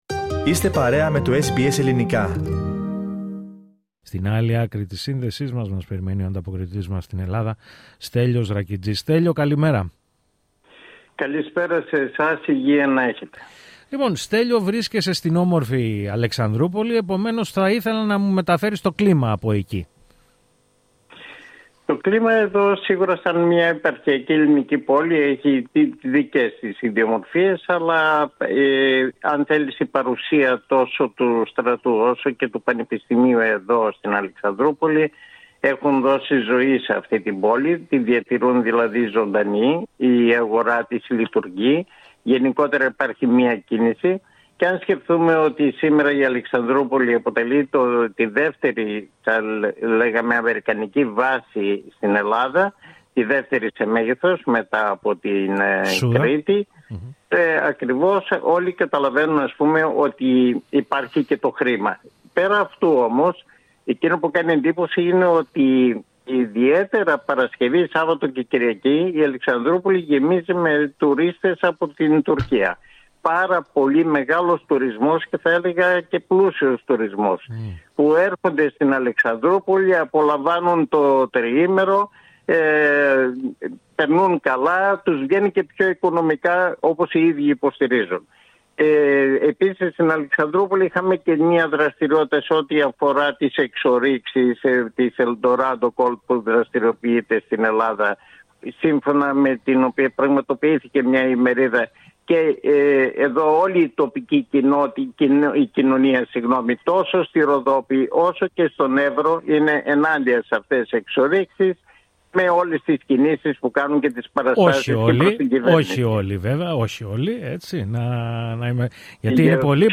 Ακούστε ολόκληρη την ανταπόκριση από την Ελλάδα, πατώντας PLAY δίπλα από την κεντρική φωτογραφία.